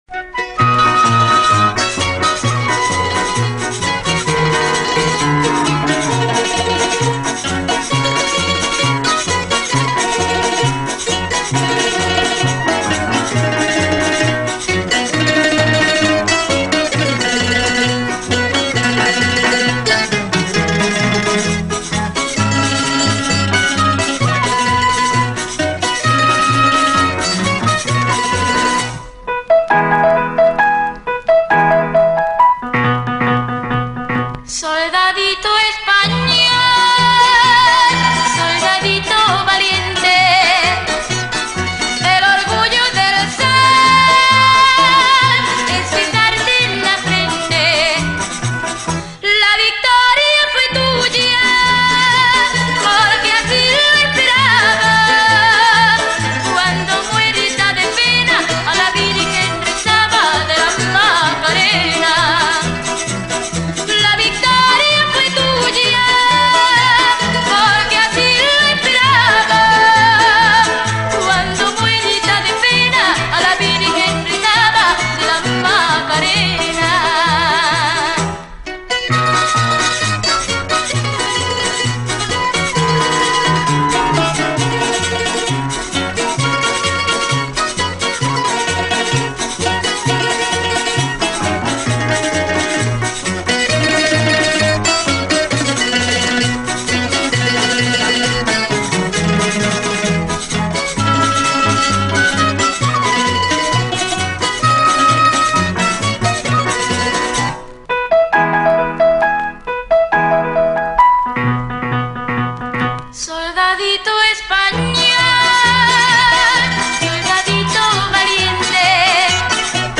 Очень солнечно...